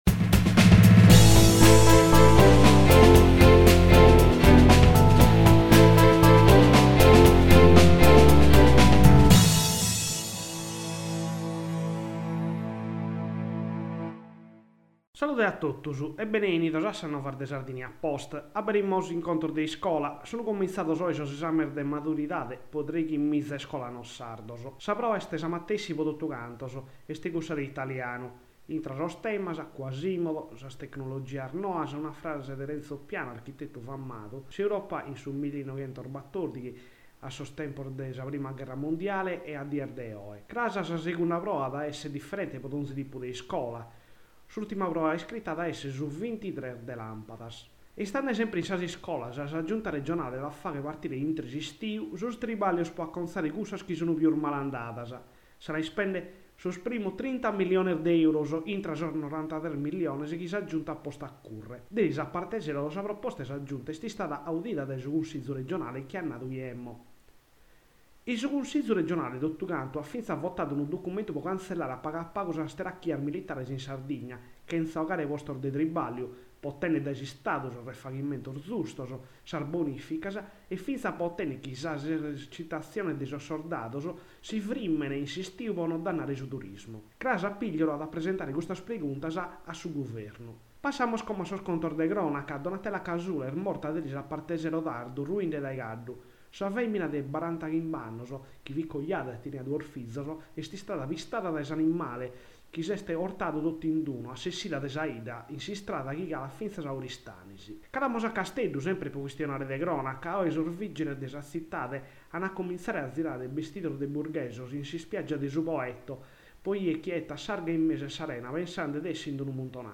Radio novas de su 18 de lampadas